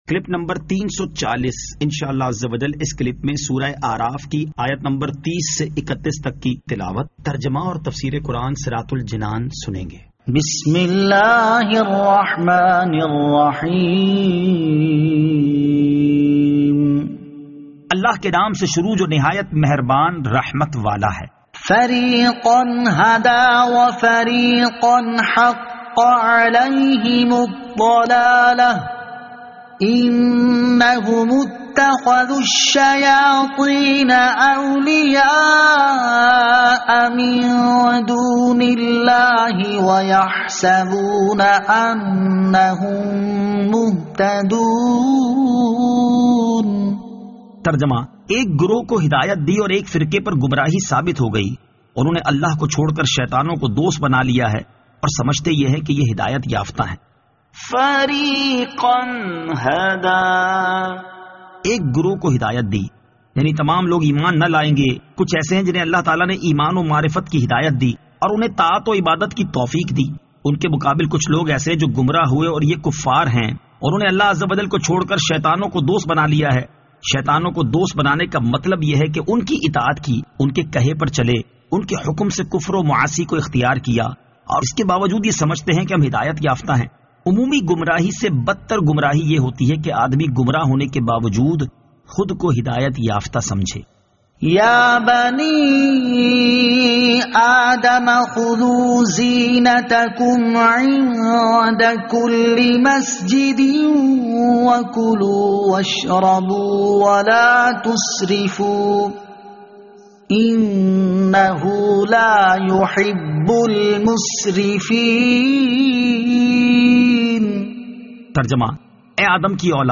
Surah Al-A'raf Ayat 30 To 31 Tilawat , Tarjama , Tafseer